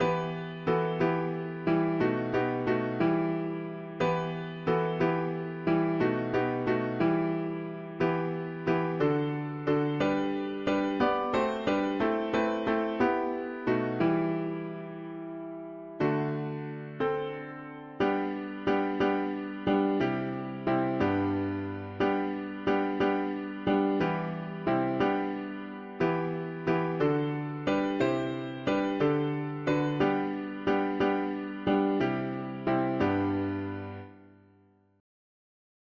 O star of wonder, star … english christian winter 4part chords
Key: G major